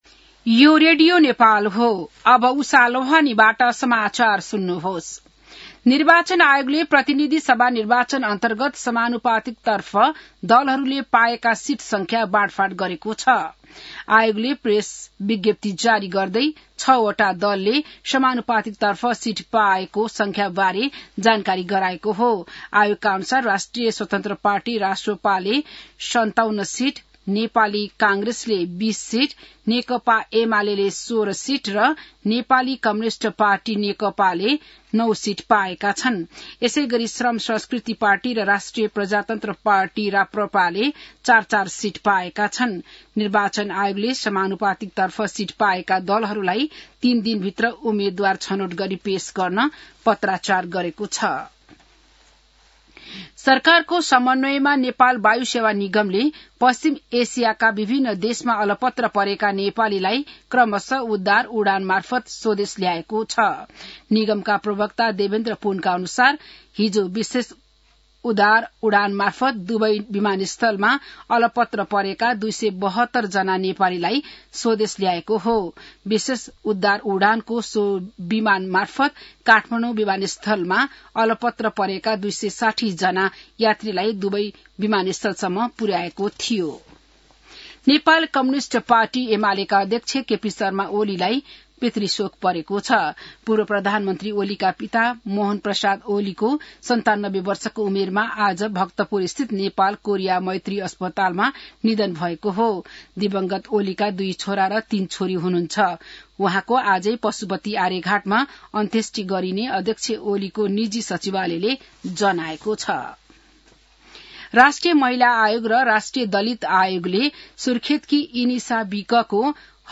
बिहान १० बजेको नेपाली समाचार : २९ फागुन , २०८२